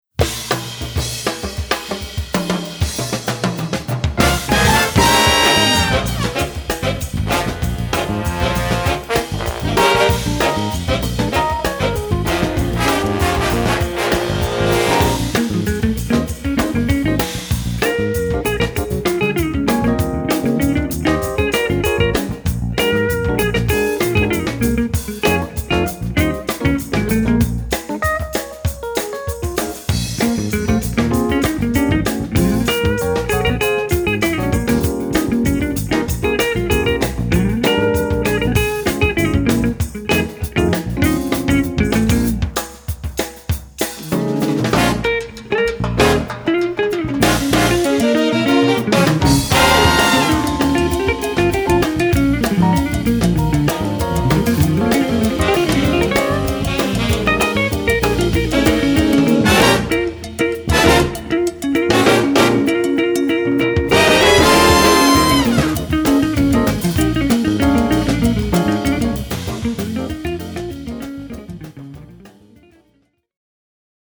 this complete Big Band of 20 musicians